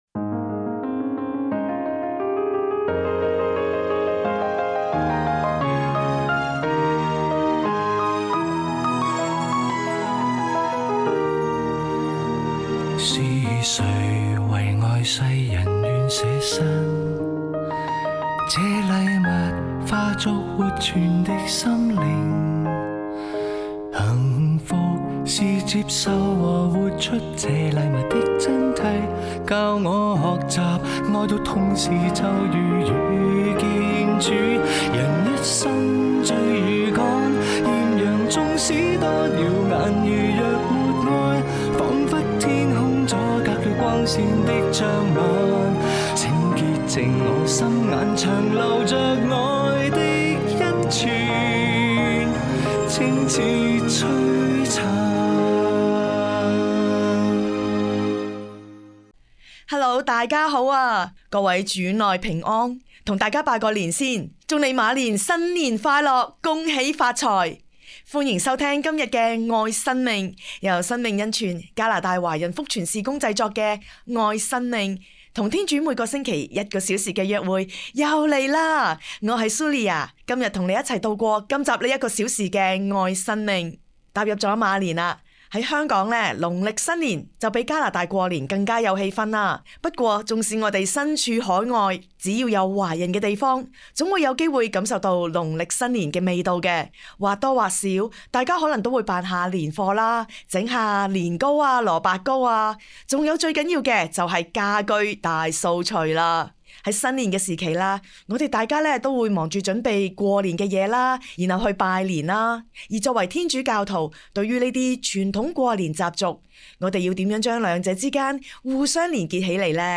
「愛 • 生命」- 二零二六年二月二十一日廣播節目全集 Radio broadcast – Full episode (February 21, 2026)